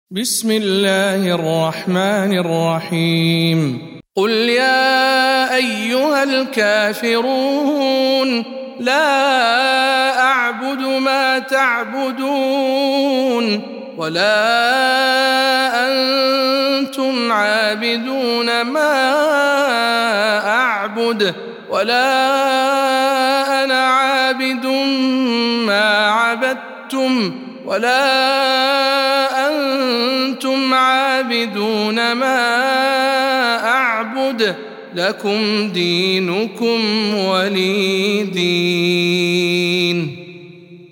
سورة الكافرون - رواية إسحاق عن خلف العاشر